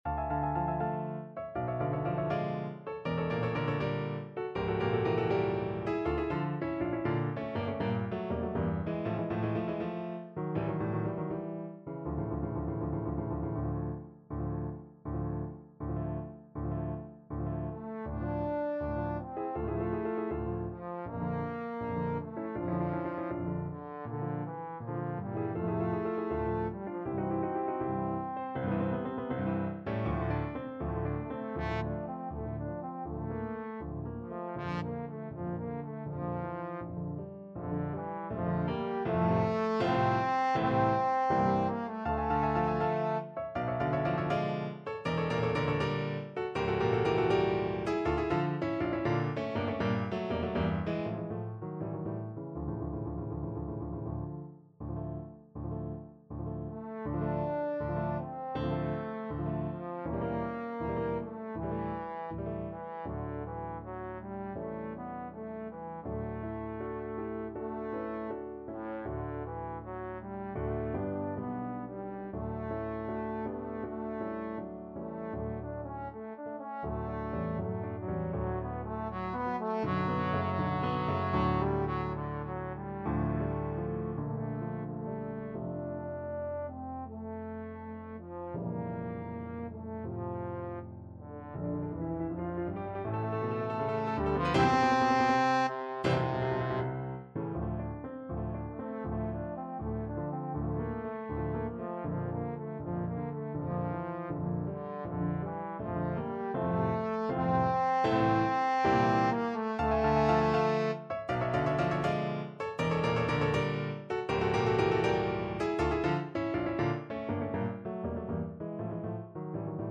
~ = 100 Moderato =80
4/4 (View more 4/4 Music)
Classical (View more Classical Trombone Music)